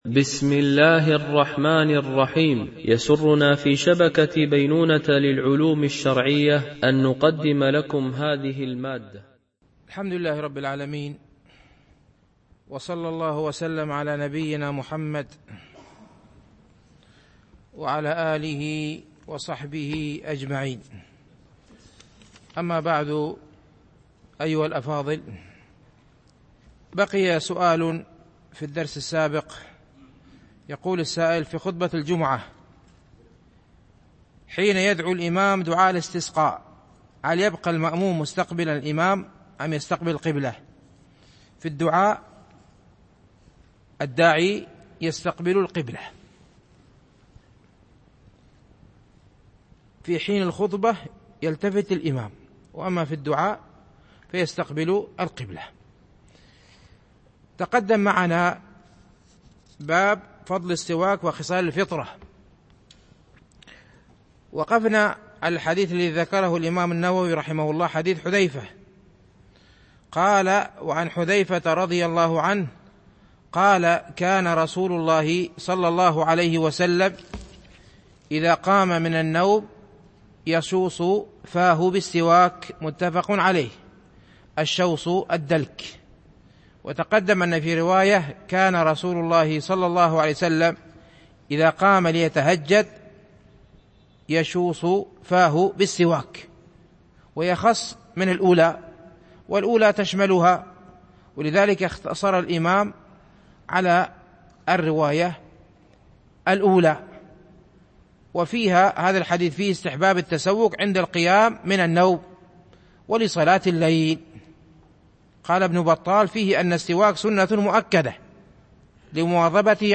شرح رياض الصالحين – الدرس 310 ( الحديث 1206 - 1210 )